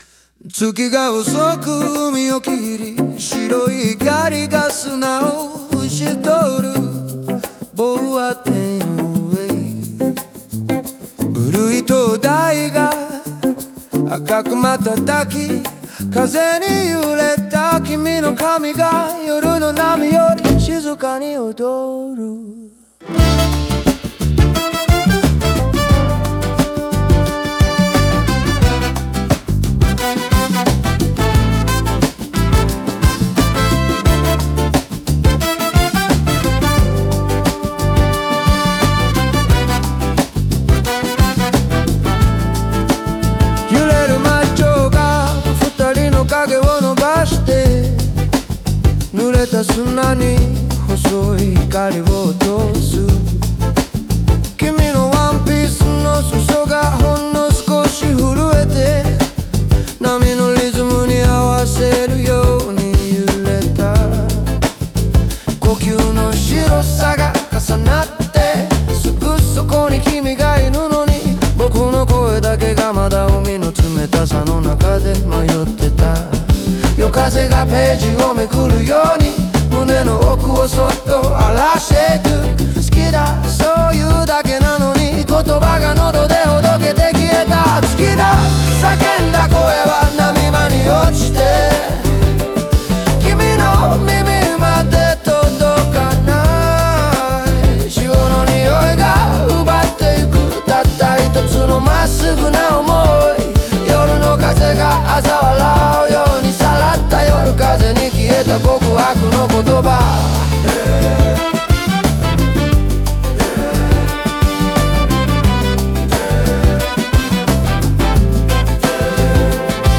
オリジナル曲♪
踏み出す勇気が風に奪われる瞬間を、躍動的なリズムと緩やかな抒情で対比させ、胸に残る後悔と温度のある想いを描き出している。